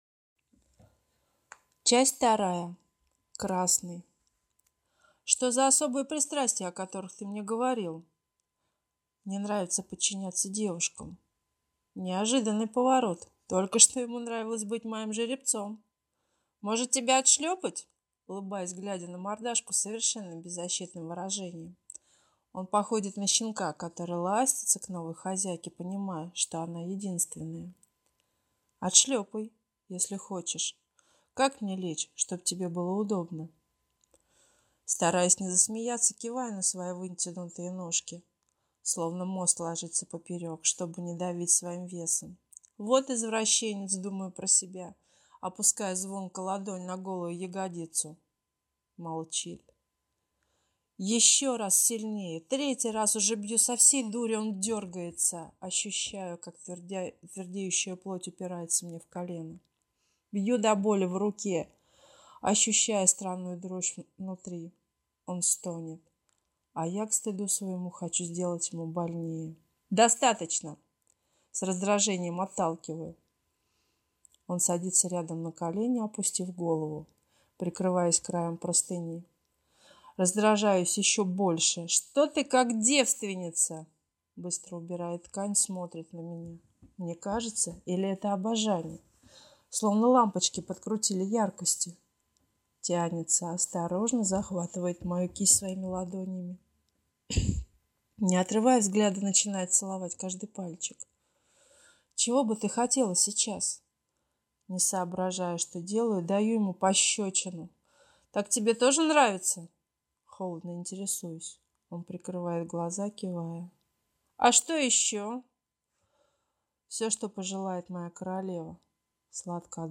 Долгожданная или не очень часть )) Хочу извиниться сразу перед слушателями, если уловят поспешность в чтении, но это обусловлено тем, что мало мб и эта попытка 6 озвучить текст, но все равно, постаралась даже в этой поспешности выразить все эмоции, которые вызвал текст.